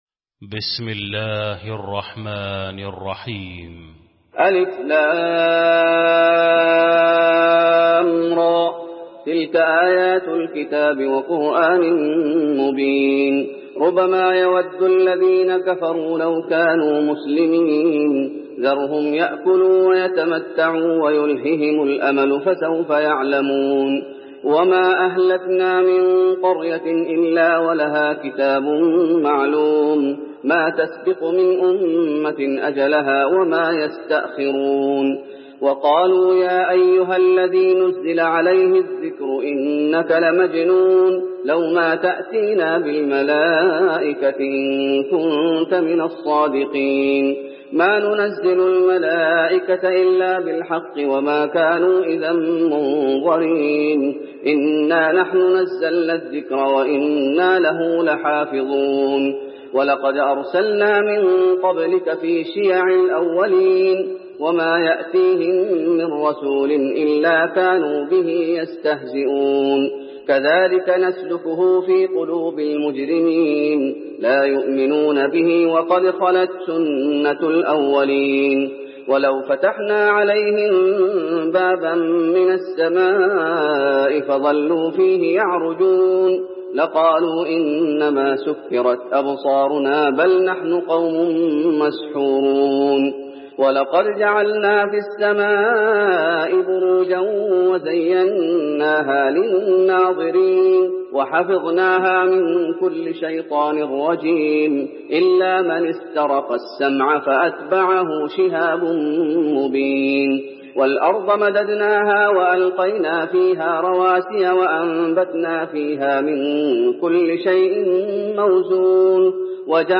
المكان: المسجد النبوي الحجر